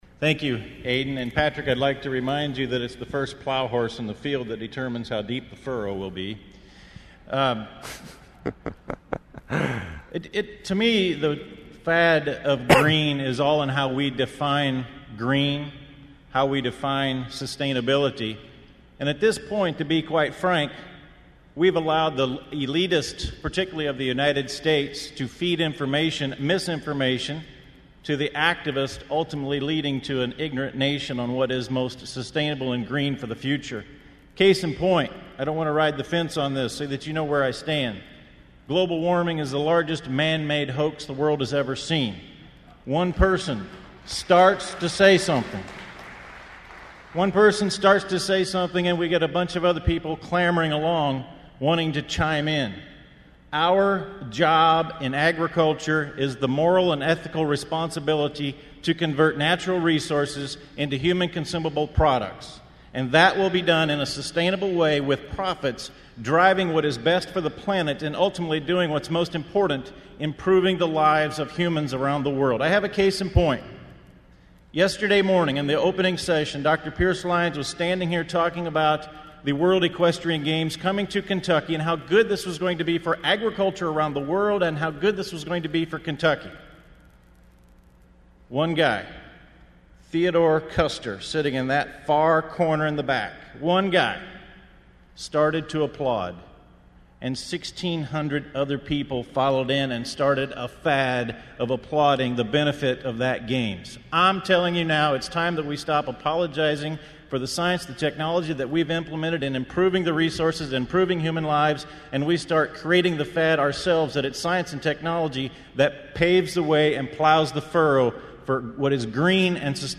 Great Debate at Alltech Symposium